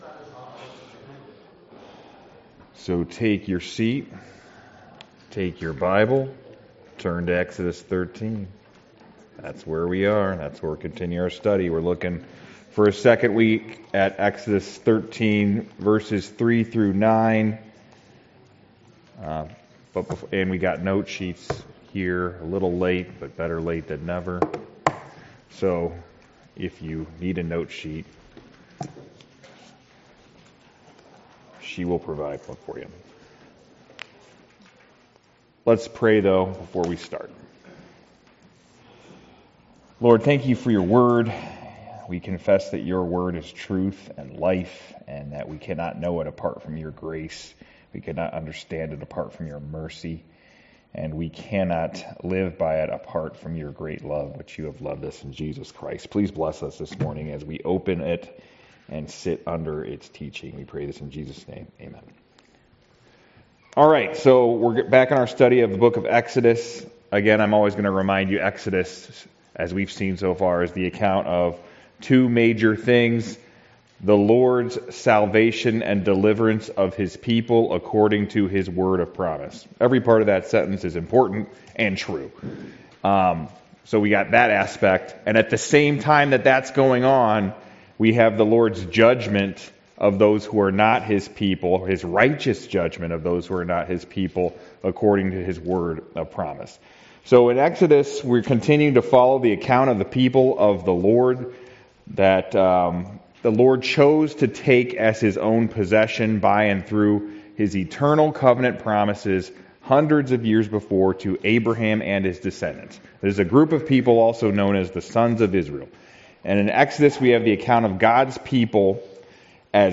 Passage: Exodus 13 Service Type: Sunday School